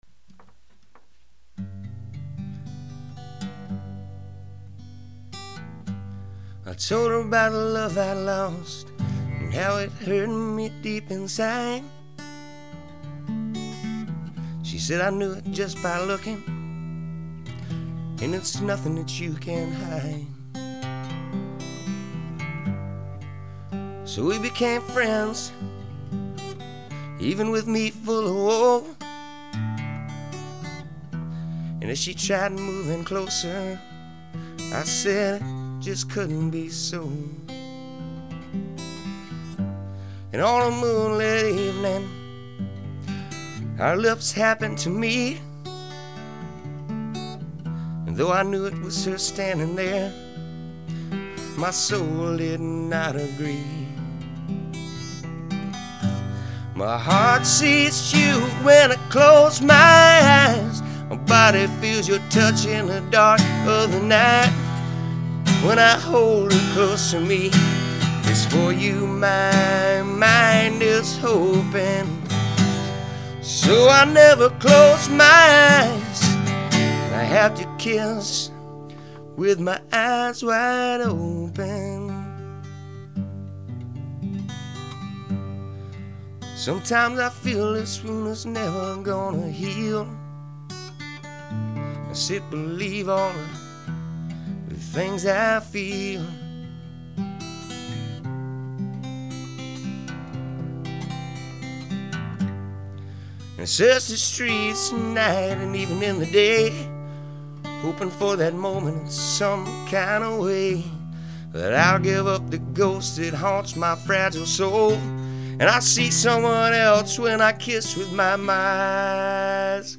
Country-rock